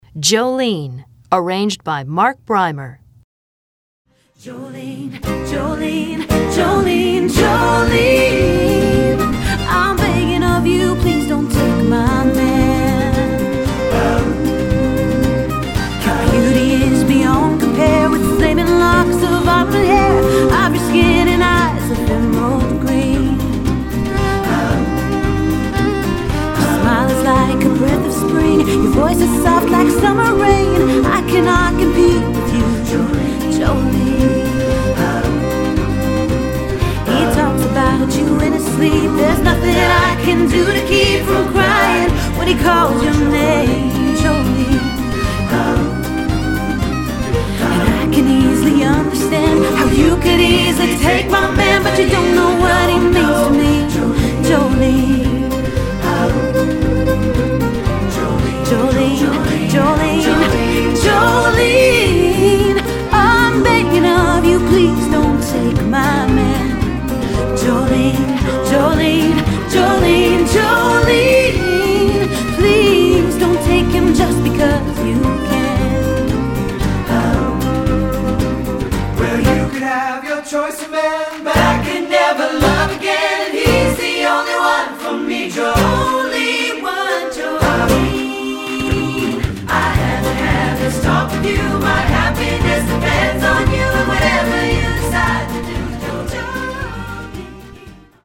Choral Early 2000's Pop 70s-80s-90s Pop
SAB